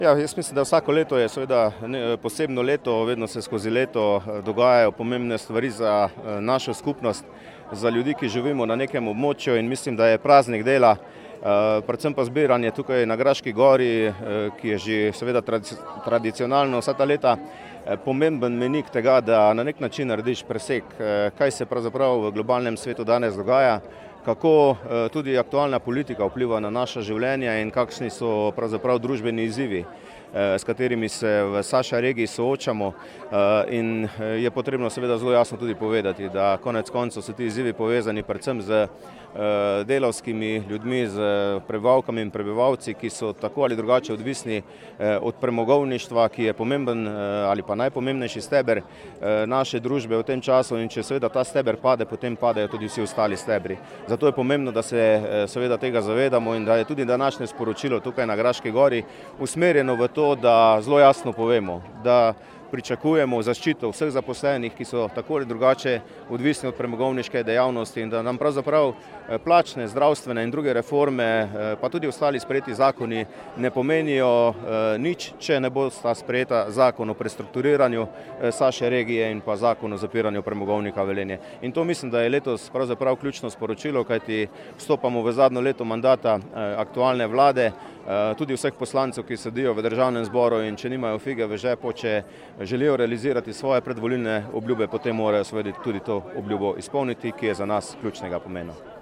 Veliko tradicionalno prvomajsko srečanje je potekalo tudi na tromeji občin Slovenj Gradec, Mislinja in Graška gora. Slavnostni govornik je bil župan Mestne občine Velenje Peter Dermol: